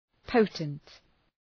Προφορά
{‘pəʋtənt}